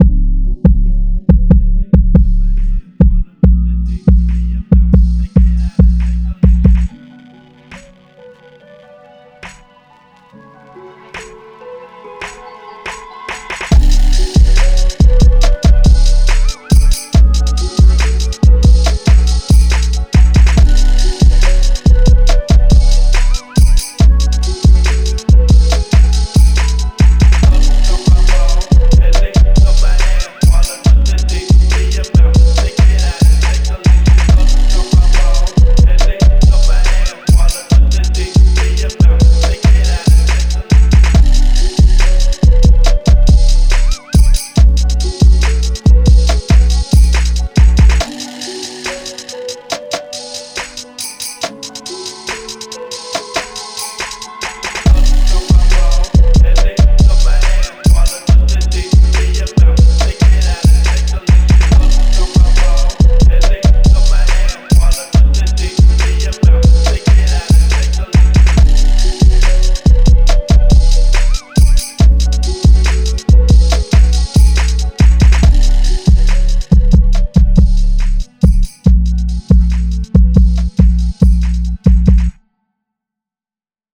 Жанр: Memphis
Hip-hop Спокойный 139 BPM